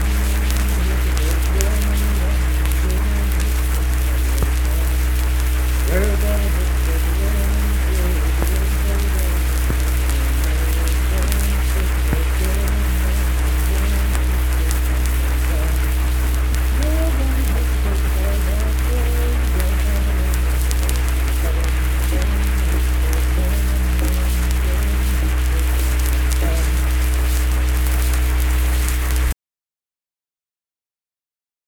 Unaccompanied vocal music
Performed in Kanawha Head, Upshur County, WV.
Dance, Game, and Party Songs
Voice (sung)